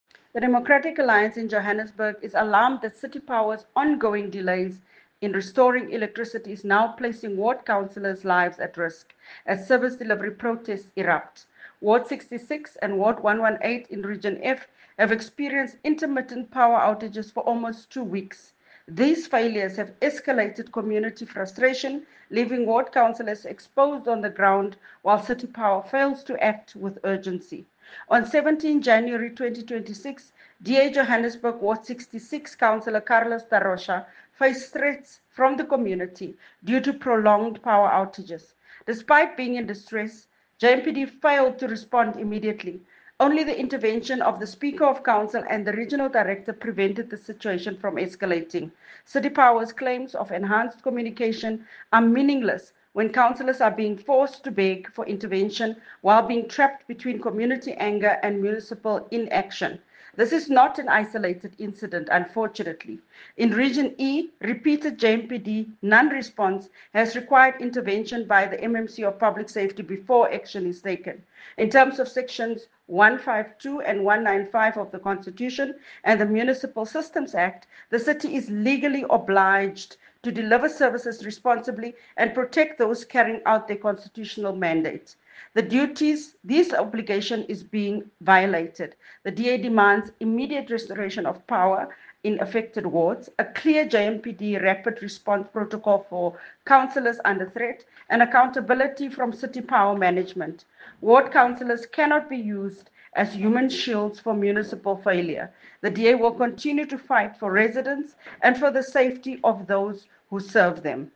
English soundbite by Cllr Belinda Kayser-Echeozonjoku